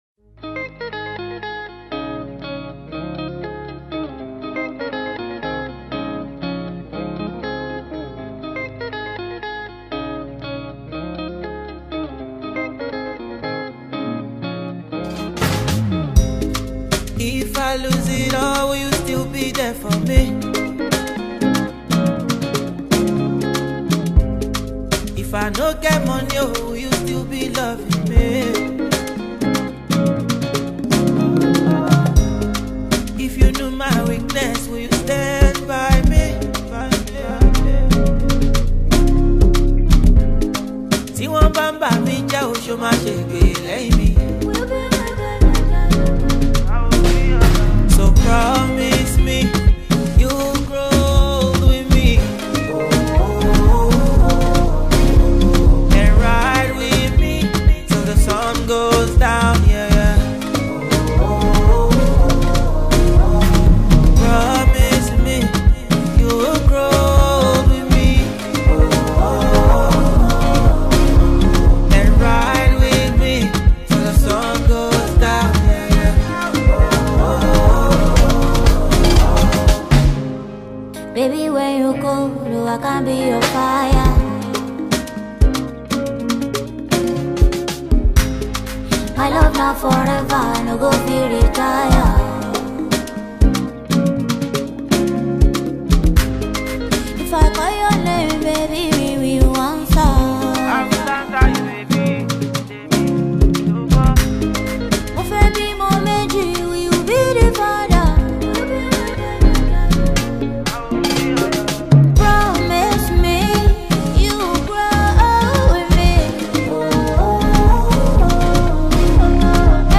He describes his genre of music as urban highlife.